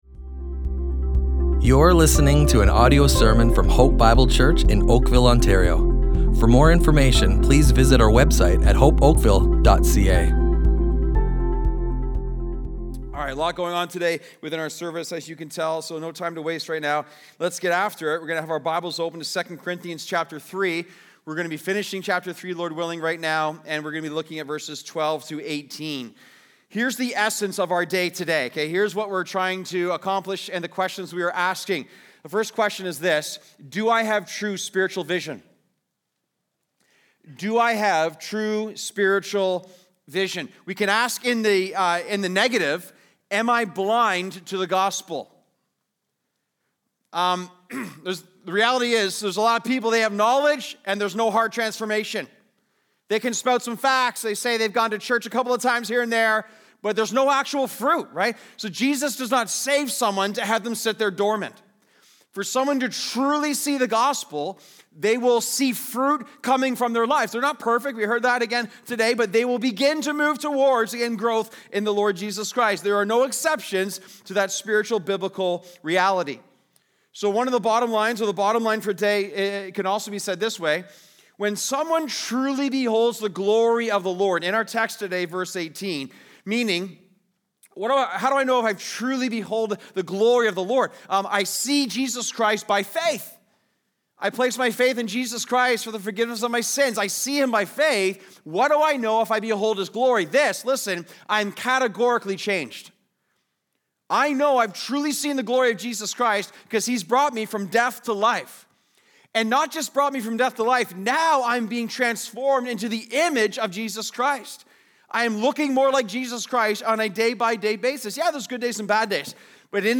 Hope Bible Church Oakville Audio Sermons Strength in Weakness // Beholding the Glory of the Lord!